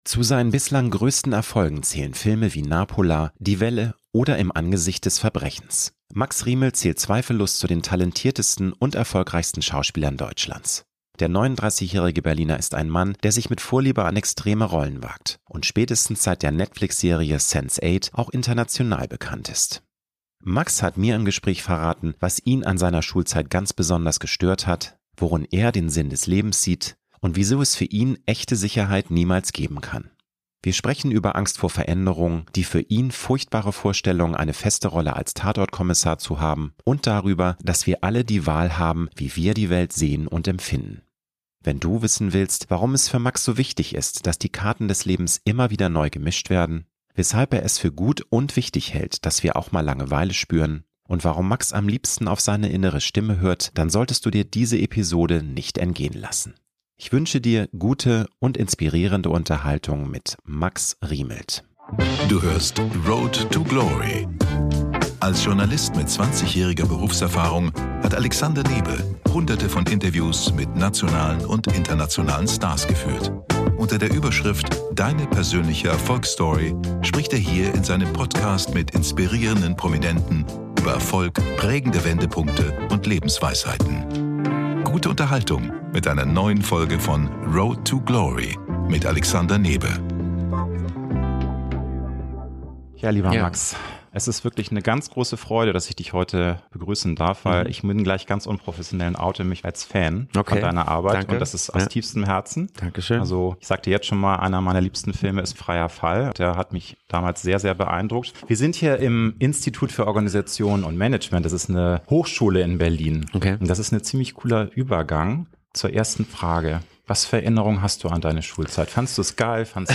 Max hat mir im Gespräch verraten, was ihn an seiner Schulzeit ganz besonders gestört hat, worin er den Sinn des Lebens sieht und wieso es für ihn echte Sicherheit niemals geben kann. Wir sprechen über Angst vor Veränderung, die für ihn furchtbare Vorstellung, eine feste Rolle als „Tatort“-Kommissar zu haben und darüber, dass wir alle die Wahl haben, wie wir die Welt sehen und empfinden.